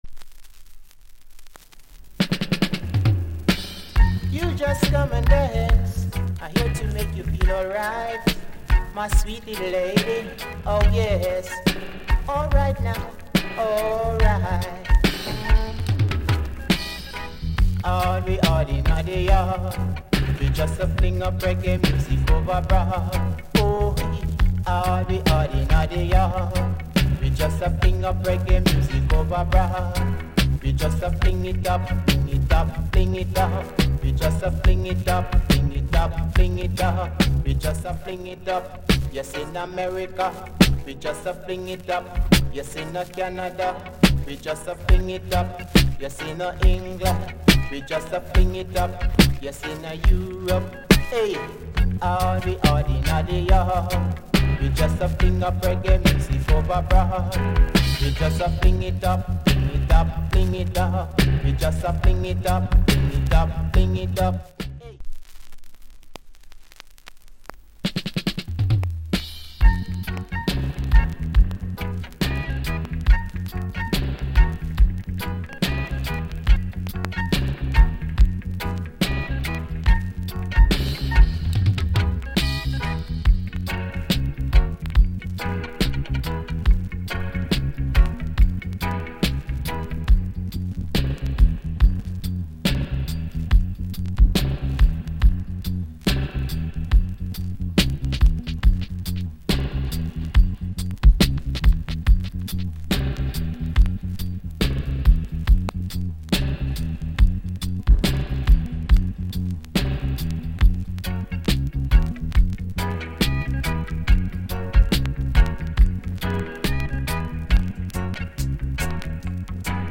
Production JA Genre Reggae80sEarly / Male Vocal